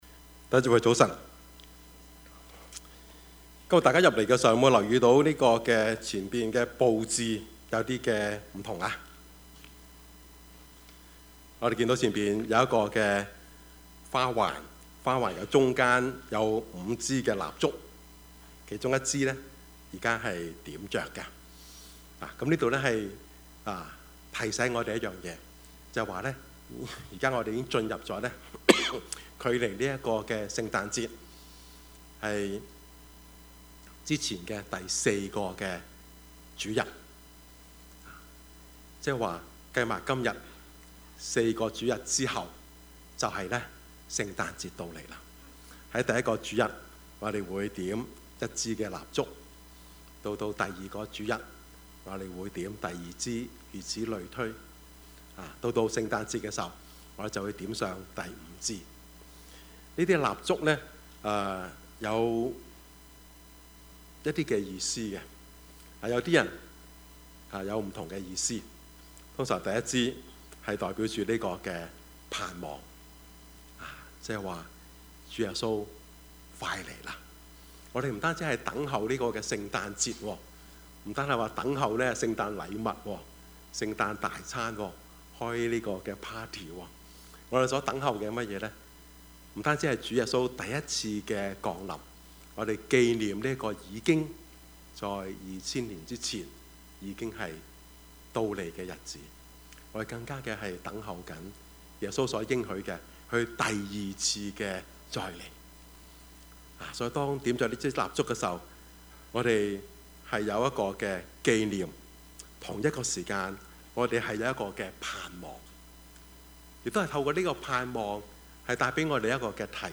Service Type: 主日崇拜
Topics: 主日證道 « 汪精衞(三) 福音的起頭 »